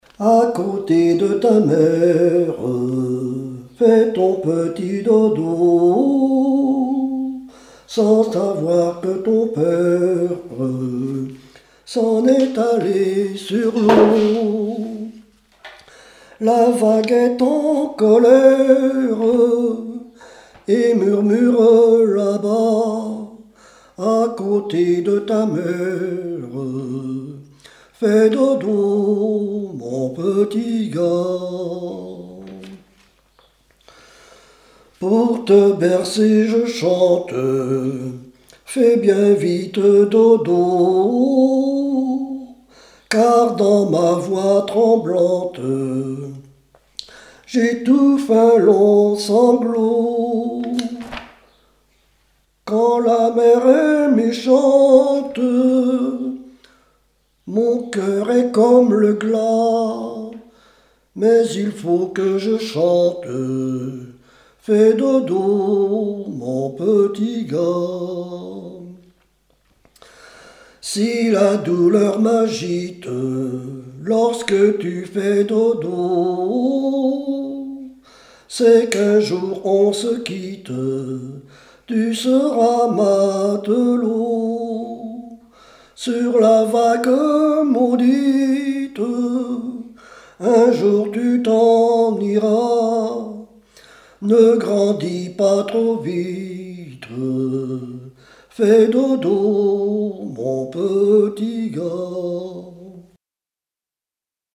Berceuses diverses
Genre strophique
Témoignages et chansons
Pièce musicale inédite